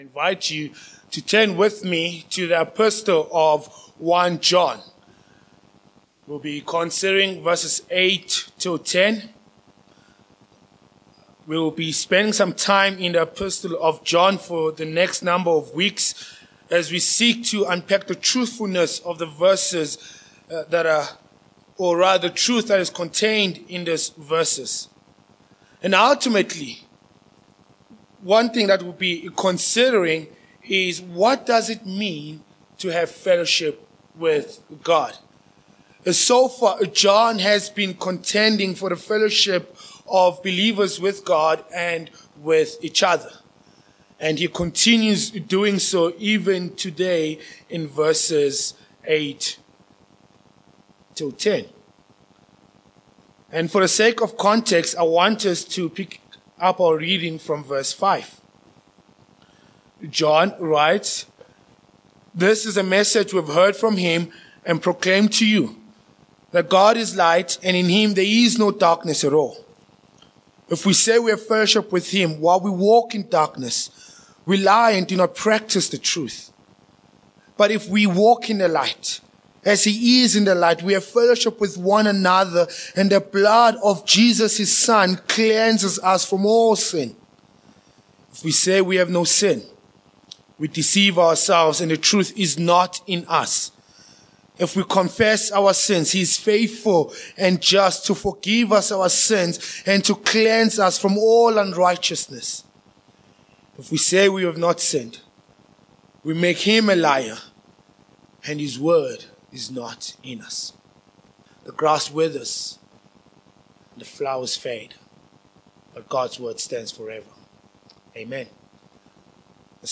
Passage: 1 John 1:8-10 Sermon Points 1.
1 John 1:8-10 Service Type: Morning Passage